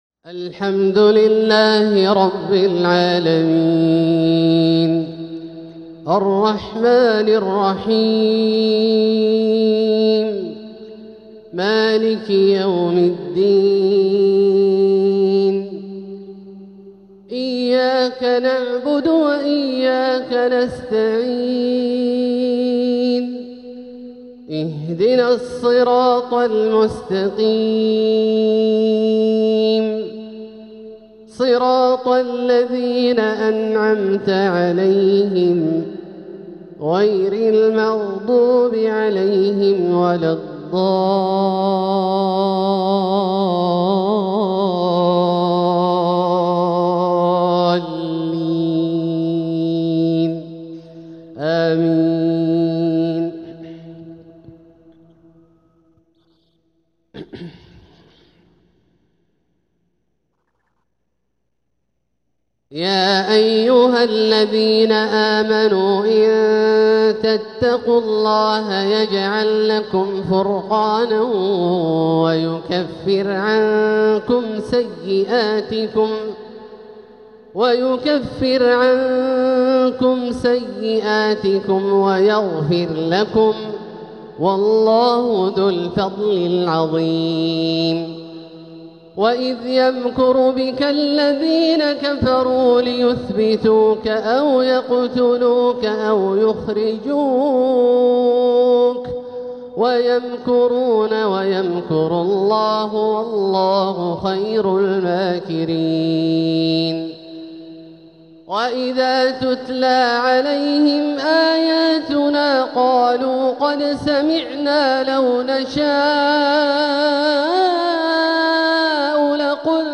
تلاوة من سورة الأنفال 29-40 | عشاء الثلاثاء 17 ربيع الأول 1447هـ > ١٤٤٧هـ > الفروض - تلاوات عبدالله الجهني